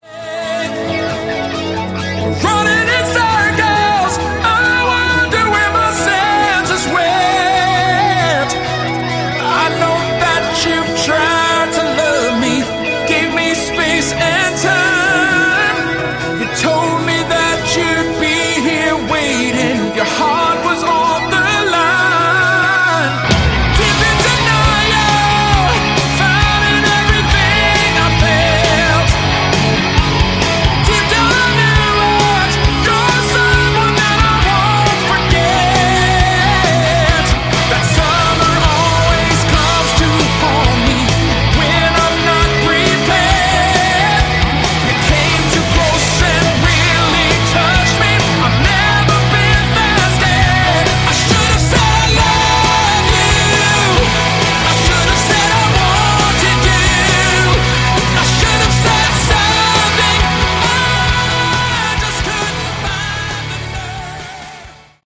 Category: AOR
lead vocals